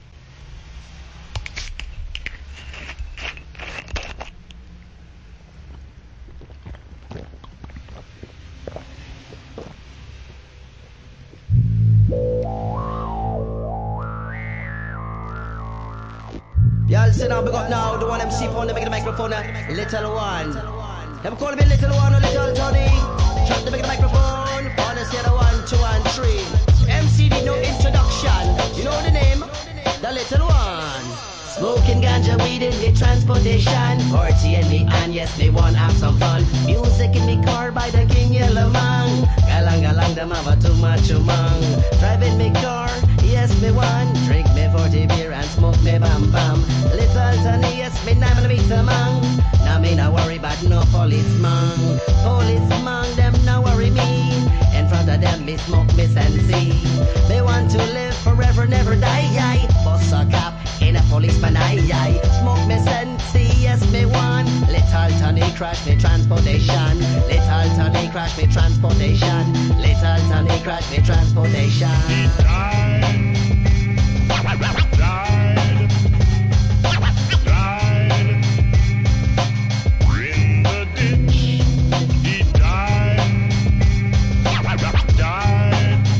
DEEPアブストラクト・ヒップホップ!! INST.のみでも十分にCOOLですが、ラガMCが絡むVER.も!!